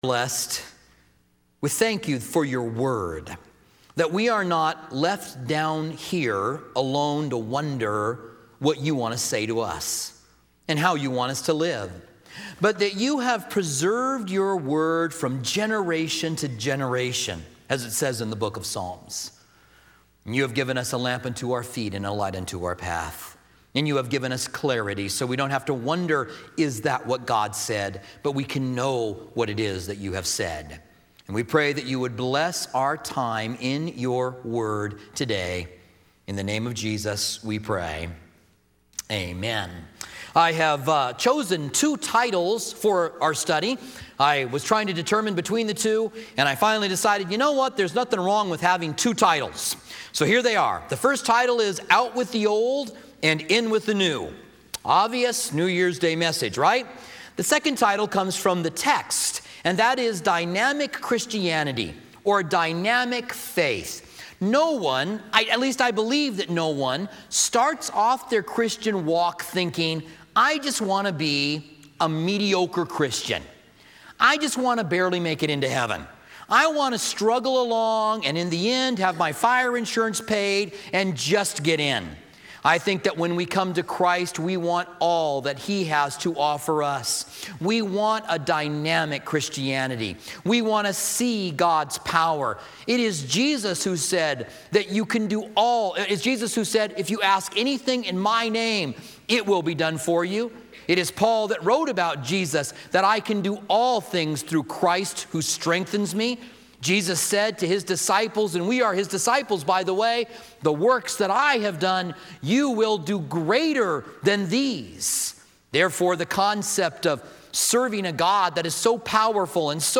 Holiday Message